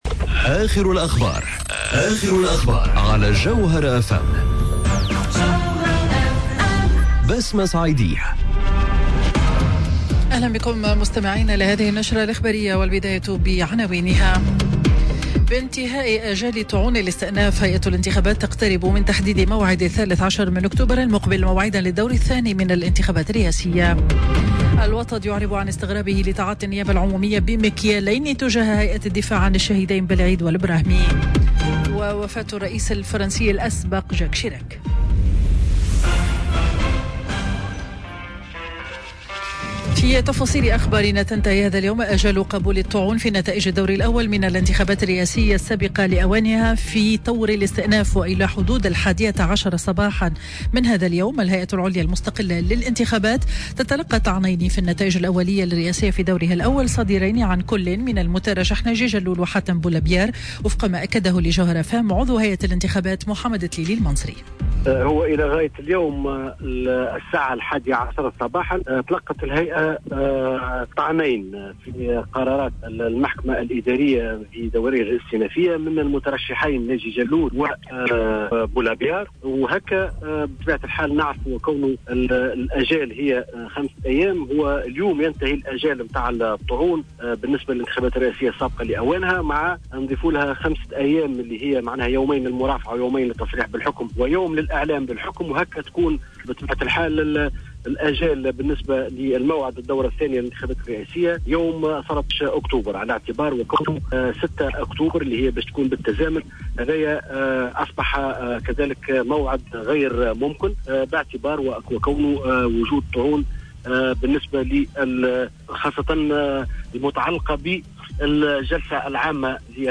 نشرة أخبار منتصف النهار ليوم الخميس 26 سبتمبر 2019